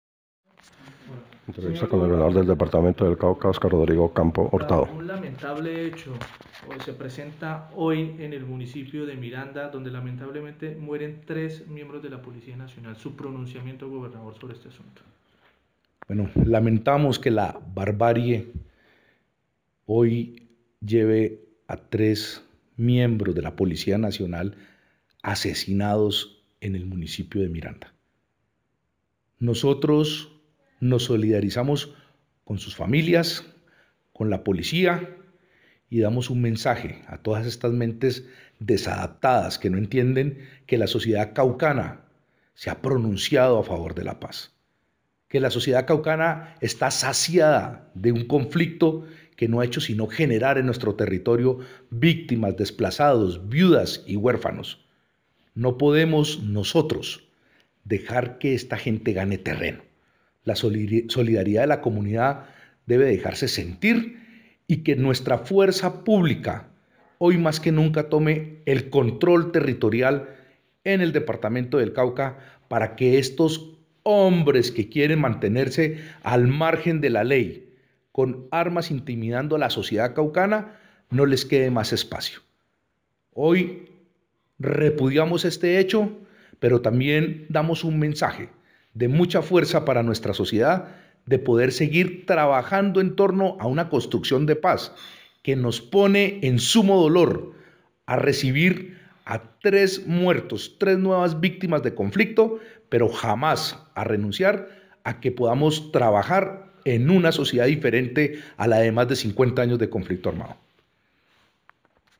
Frente a la situación presentada en el municipio de Miranda, Cauca, donde resultaron asesinados tres policías en circunstancias que son materia de investigación, el Gobernador del Cauca, Óscar Rodrigo Campo Hurtado, repudió el hecho enviando un mensaje enfático de que se continuará trabajando en la consolidación de paz en el territorio caucano
Gobernador-del-Cauca-condena-muerte-de-Policías.mp3